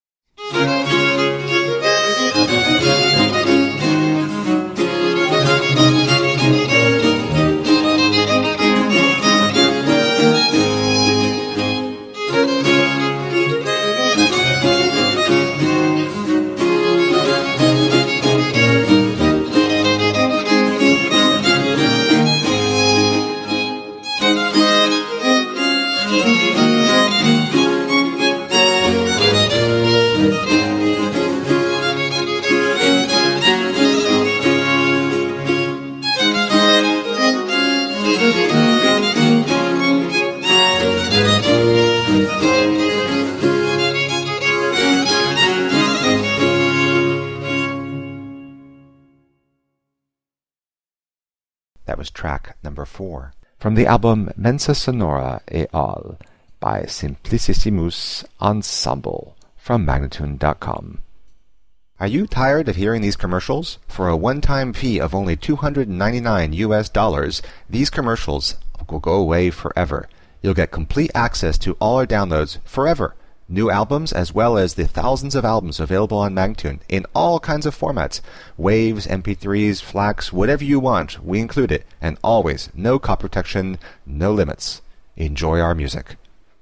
17th and 18th century classical music on period instruments.